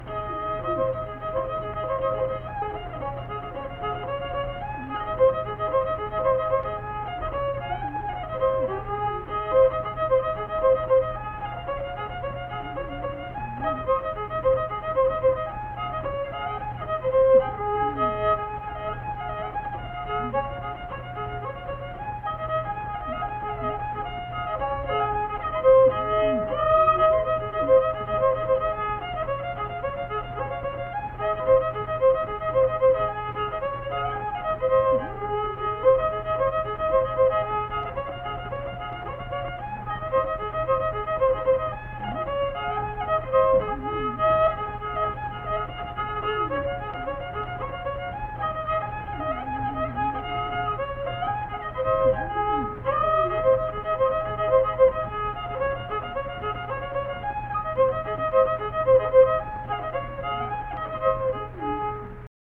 Unaccompanied fiddle music performance
Instrumental Music
Fiddle
Marion County (W. Va.), Mannington (W. Va.)